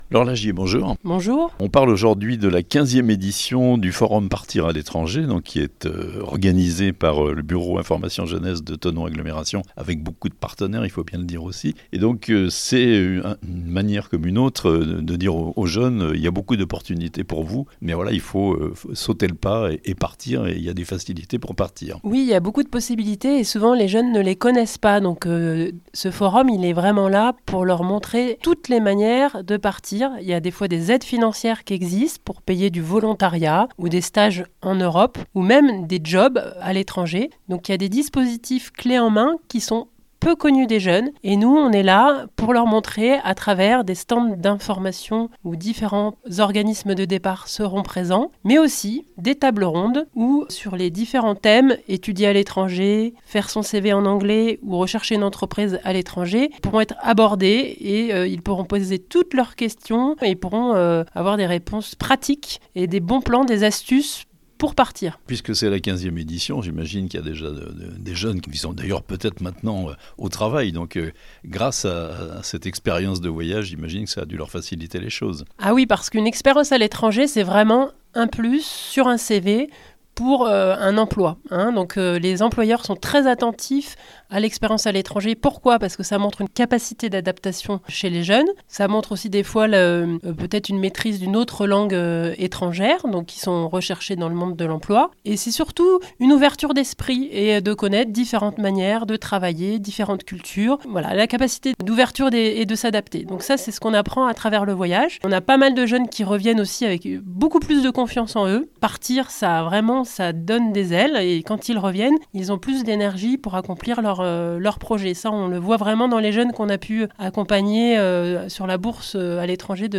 Thonon Agglomération : la 15ème édition du forum "Partir à l'étranger" aura lieu le 23 novembre (interview)